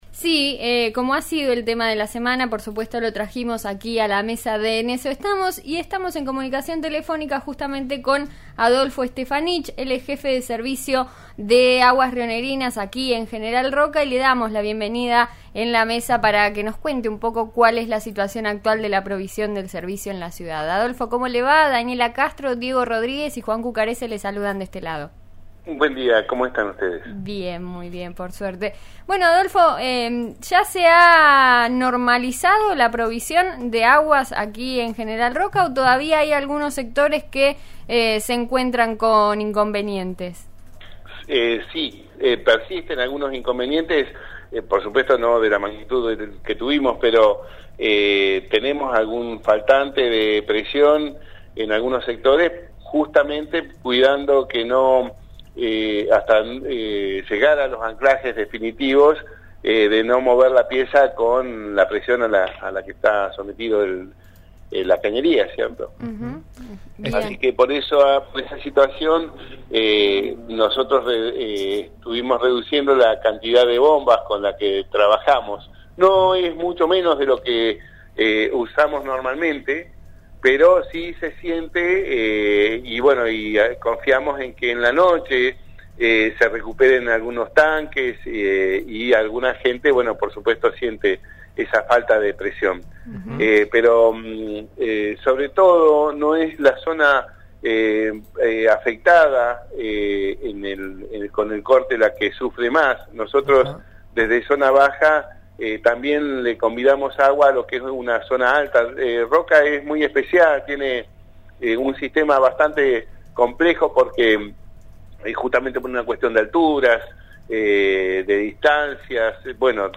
Escuchá la entrevista completa para saber cuando se estabilizará el servicio en la ciudad: